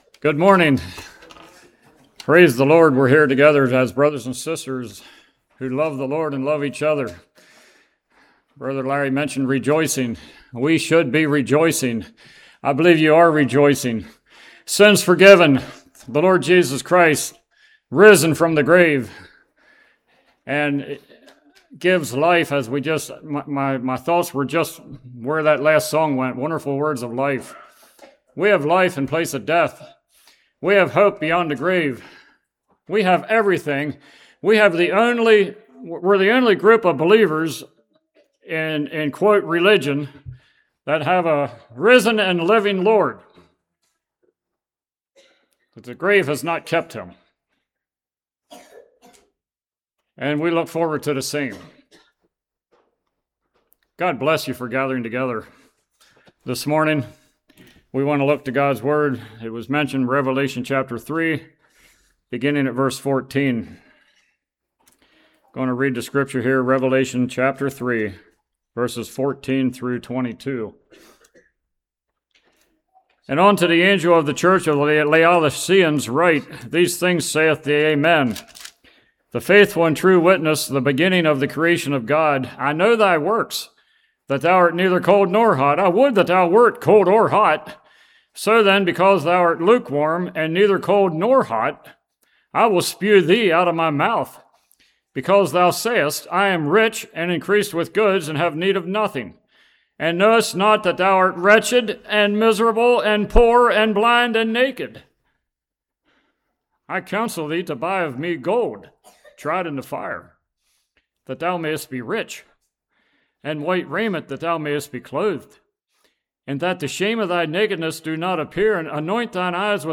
Revelation 3:14-22 Service Type: Morning I know about the Lord.